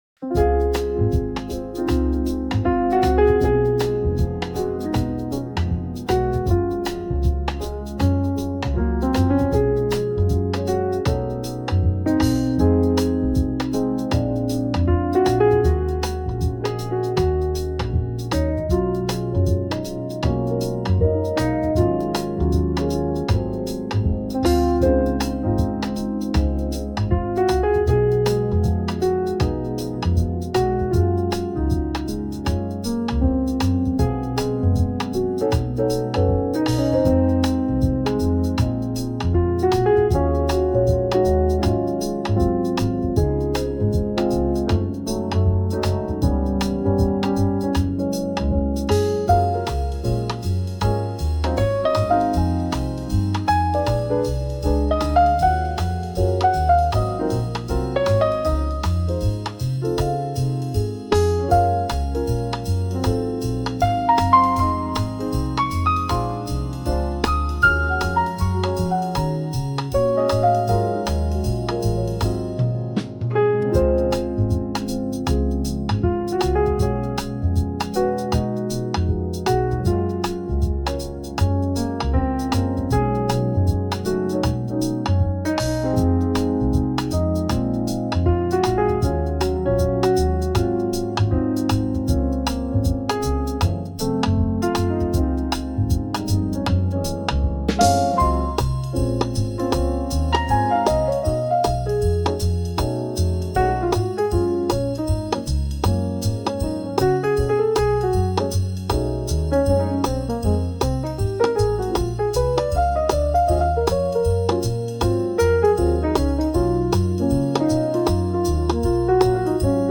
スムースジャズ・インストゥルメンタル・ボーカル無し